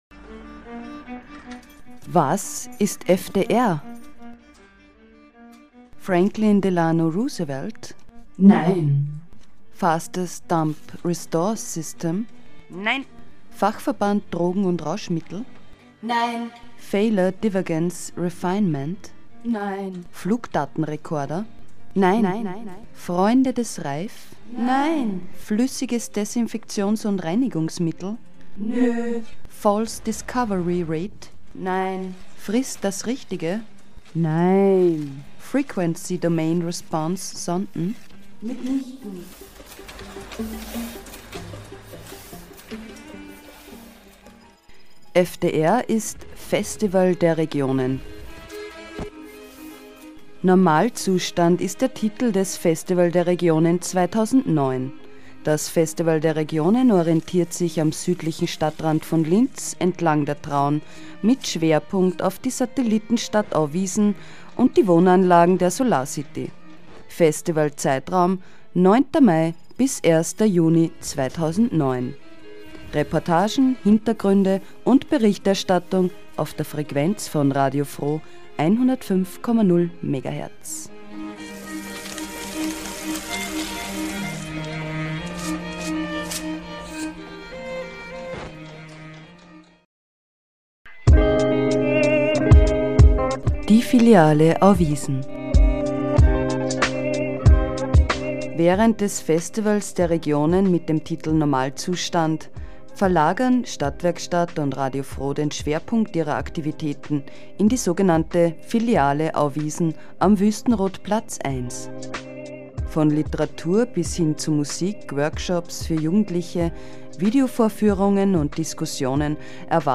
In dieser Ausgabe von Live aus Auwiesen geht es um Marcel Callo. Sie hören einen Radiofeature und Interviews zum Projekt 20 bis 24 heute.
Format: Stereo 44kHz